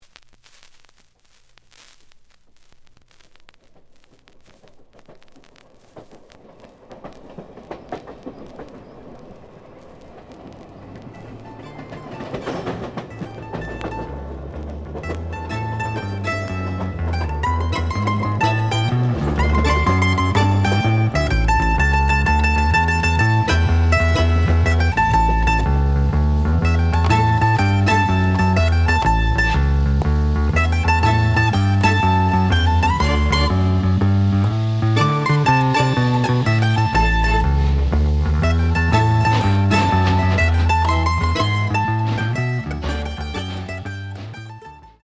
The audio sample is recorded from the actual item.
●Format: 7 inch
●Genre: Rock / Pop
Slight edge warp.